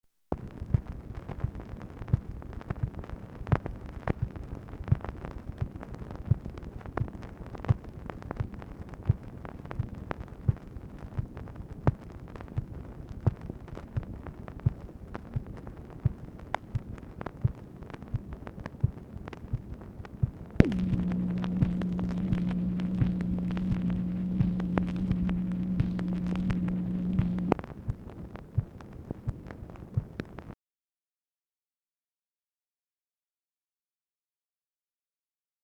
MACHINE NOISE, March 10, 1966
Secret White House Tapes | Lyndon B. Johnson Presidency